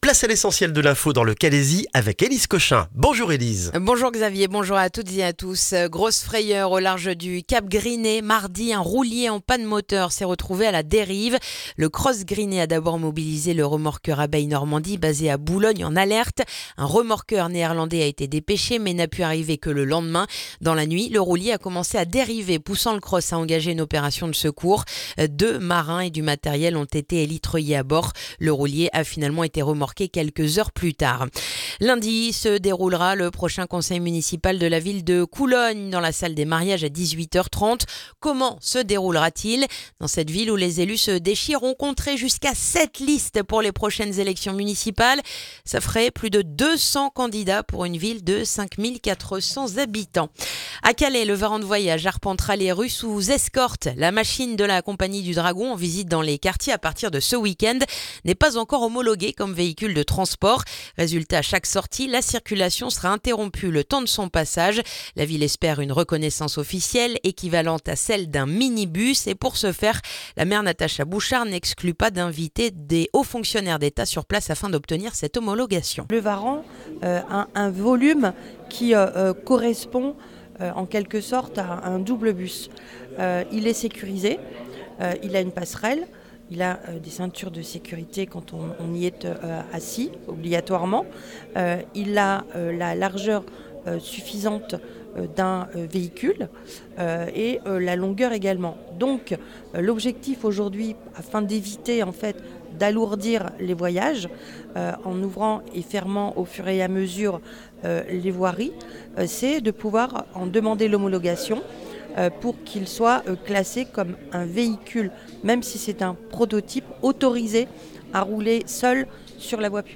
Le journal du vendredi 23 janvier dans le calaisis